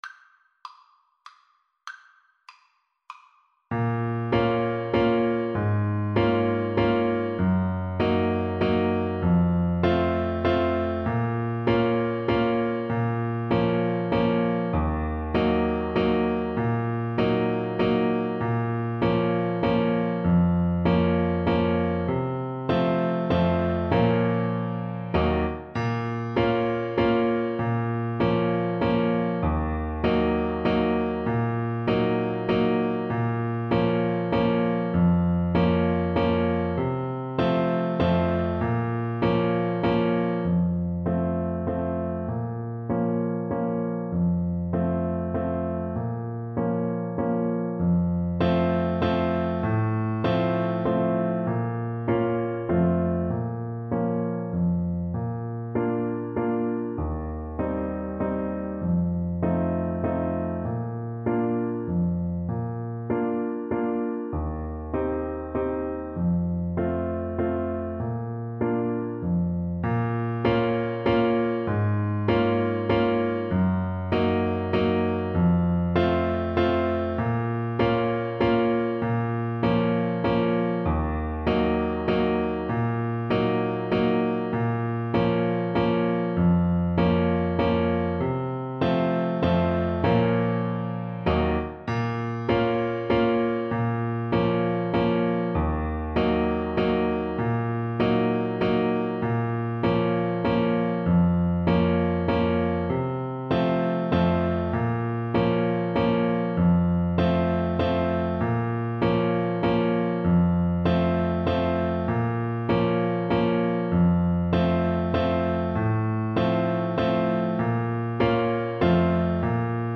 Clarinet
Traditional Music of unknown author.
3/4 (View more 3/4 Music)
Eb5-D7
Bb minor (Sounding Pitch) C minor (Clarinet in Bb) (View more Bb minor Music for Clarinet )
Molto allegro =c.140